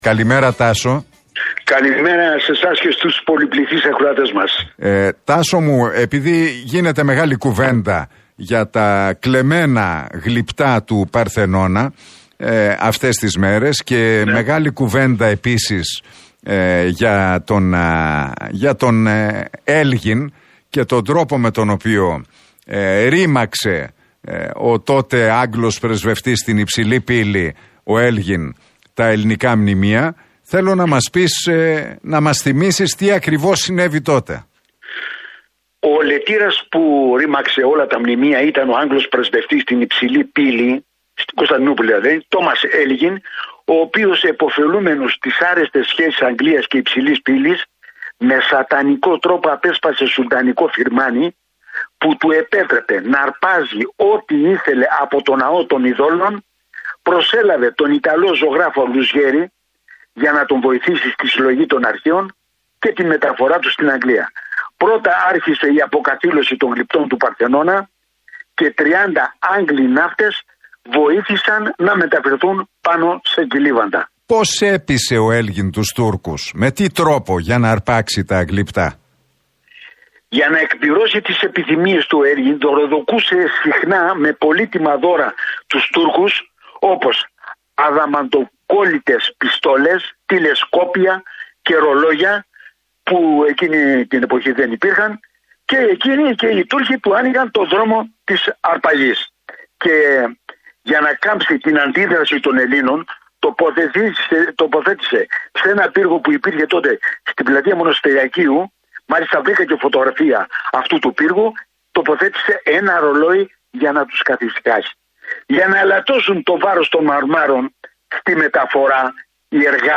μιλώντας στον Realfm 97,8 και στην εκπομπή του Νίκου Χατζηνικολάου.